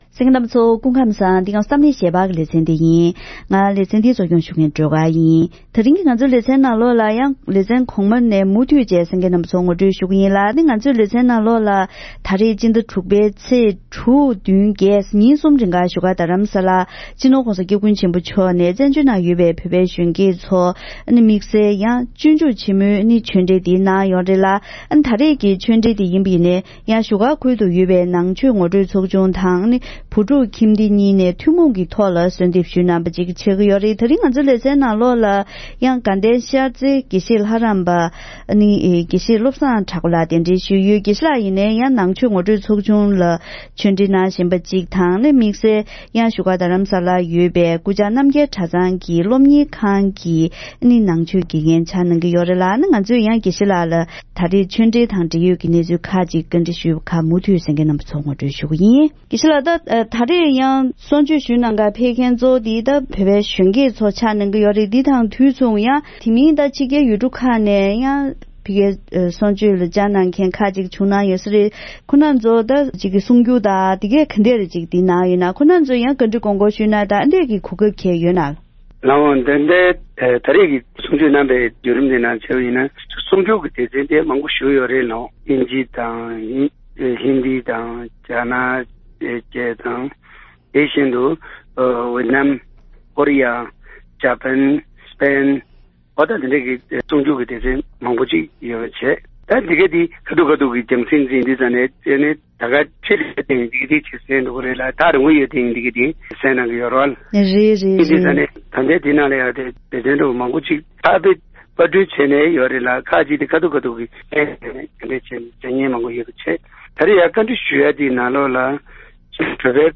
ཉེ་ཆར་༧གོང་ས་མཆོག་ནས་བོད་པའི་གཞོན་སྐྱེས་ཚོར་བཀའ་ཆོས་གནང་སྐབས་ཉིན་རེའི་འཚོ་བ་དང་བསམ་བློ་འཁྱེར་ཕྱོགས་ཐད་བཀའ་སློབ་བསྩལ་གནང་མཛད་ཡོད་པ།